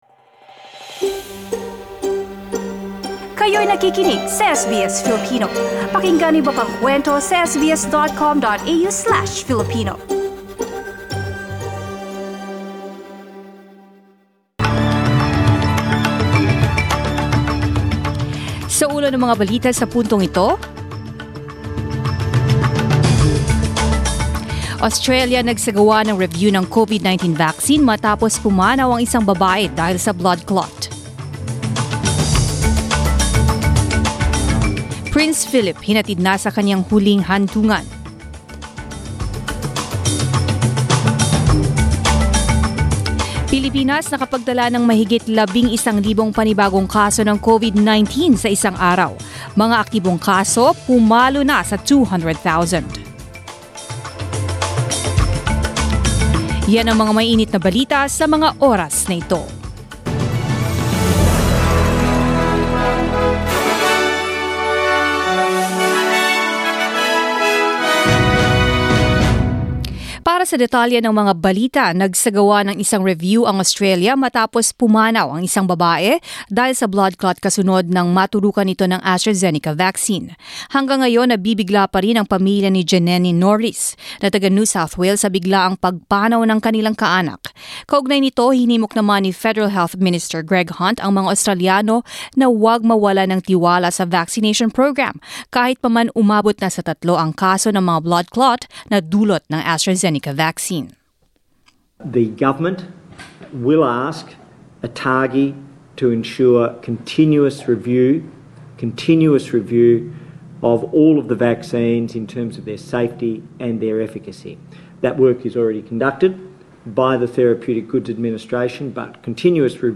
Mga balita ngayong ika-18 ng Abril